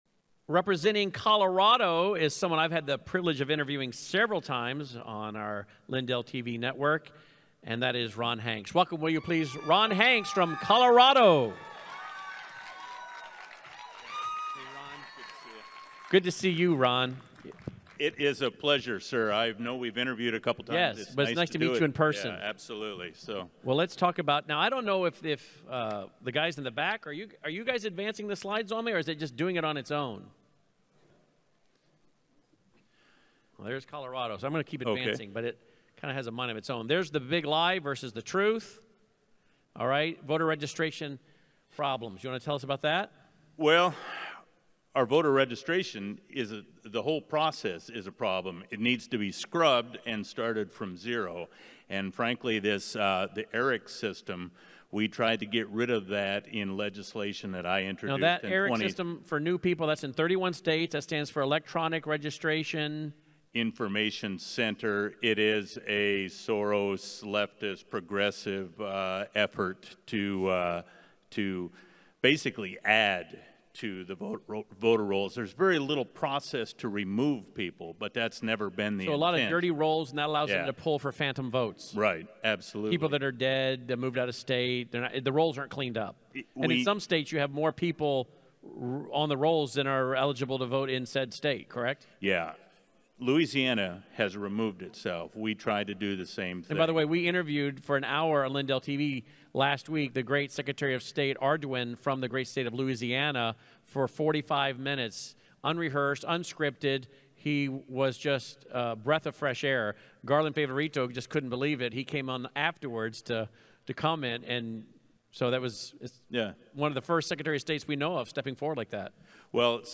2022 CO Moment of Truth Summit State of the States Presentation audio – Cause of America
presentation